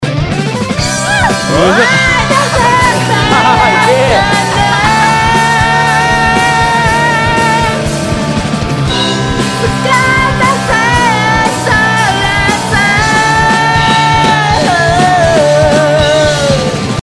間奏後のオク上げボーカル↓を聴きたかった。